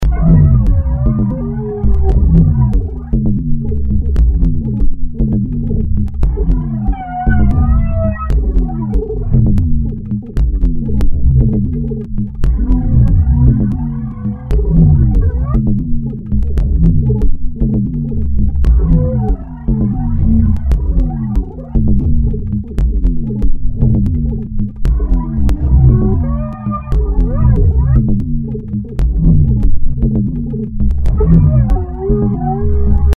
Die gefilterte Bassdrum gibt nun eigentümlich wimmernde Geräusche von sich.
Eine Art Beschwörung der Maschine.